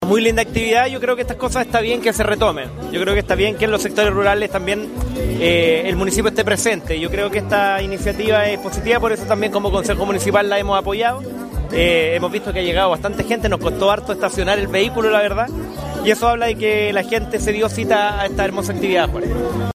CONCEJAL FRANCO ARAVENA
CONCEJAL-FRANCO-ARAVENA_01.mp3